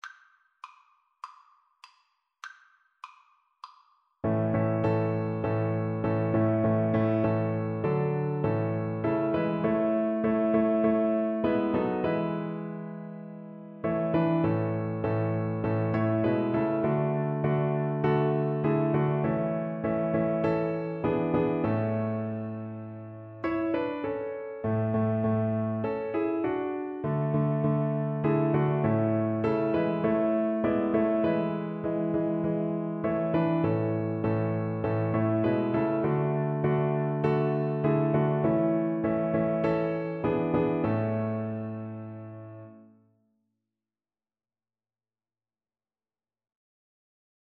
Violin version
Christian Christian Violin Sheet Music Are You Washed in the Blood?
Violin
4/4 (View more 4/4 Music)
A major (Sounding Pitch) (View more A major Music for Violin )